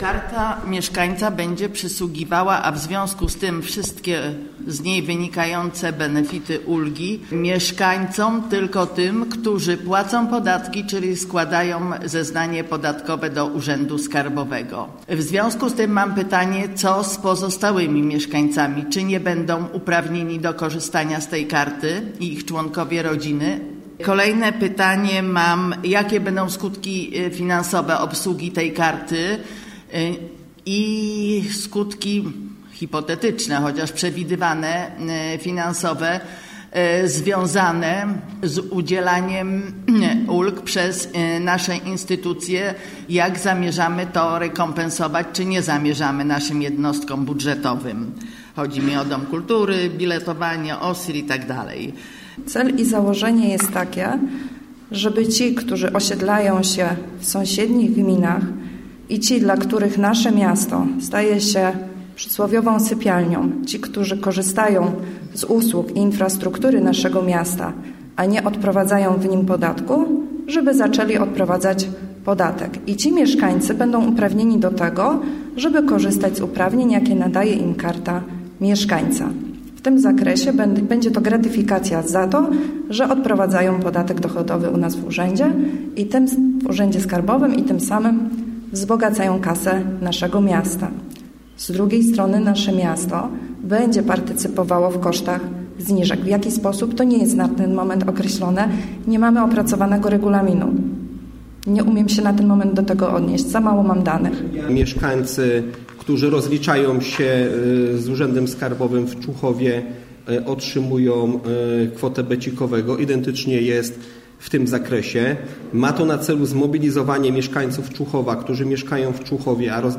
obrady sesji
plik dźwiękowy - dyskusja